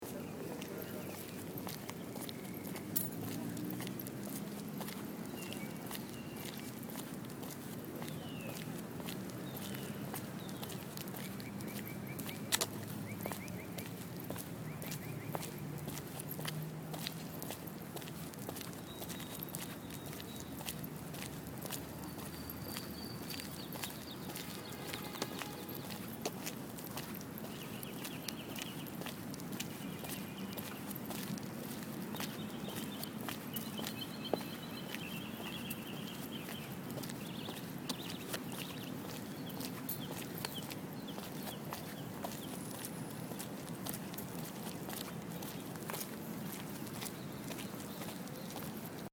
Field Recording #7
A beautiful Spring morning: you can hear my footsteps, my keys jingling, and the birds singing.
Walking-to-class-with-birds.mp3